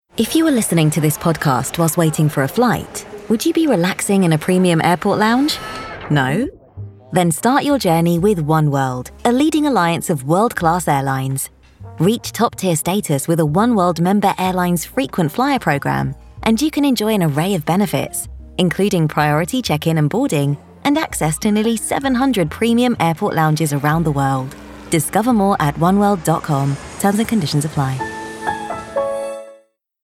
M_1_PodcastAdvert_ENG_30__1_.mp3